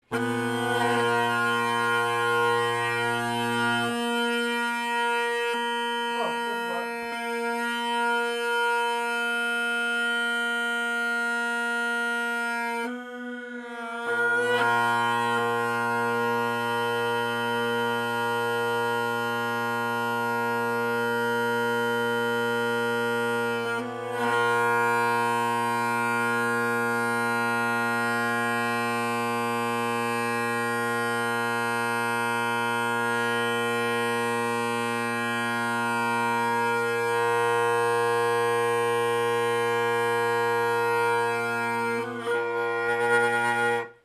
The glass Rockets below are definitely bolder than the carbon Rockets above (same recording conditions, sorry there’s no chanter).